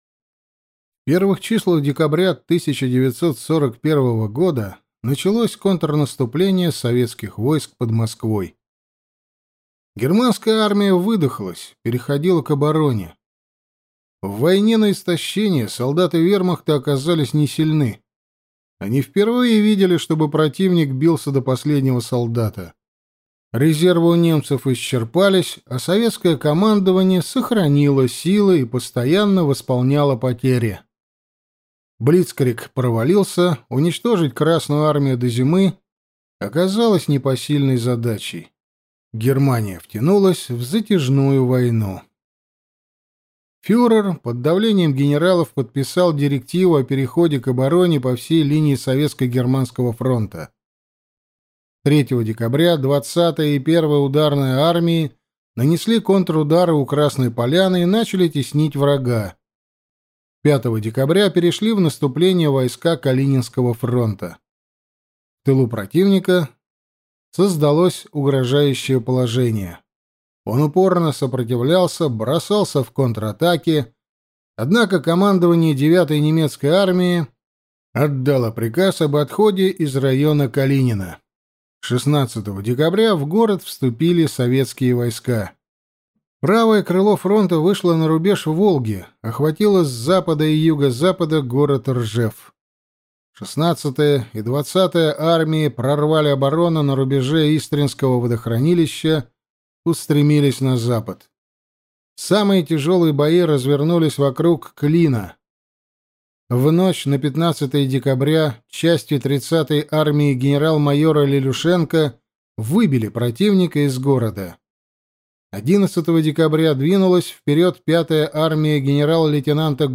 Аудиокнига Свой с чужим лицом | Библиотека аудиокниг
Прослушать и бесплатно скачать фрагмент аудиокниги